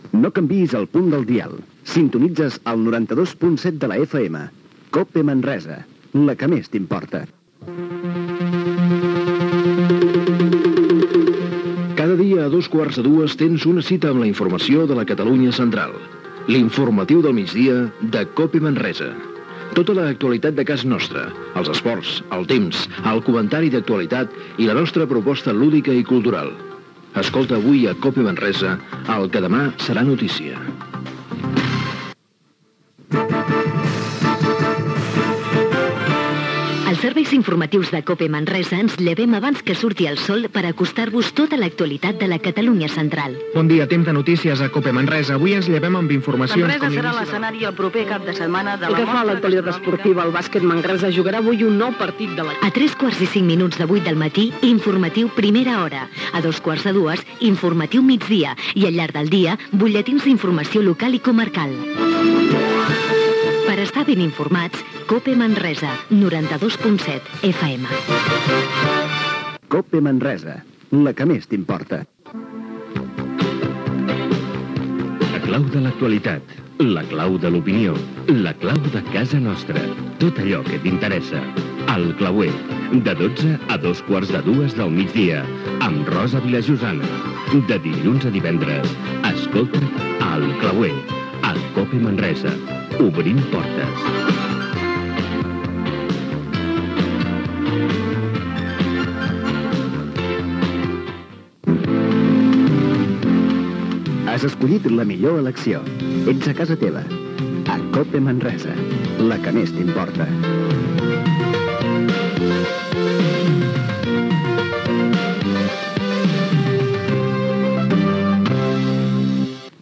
Indicatiu de l'emissora, promoció de "L'informatiu del migdia de COPE Manresa" i dels Serveis informatius de COPE Manresa, indicatiu de l'emissora, promoció del programa "El clauer", indicatiu
FM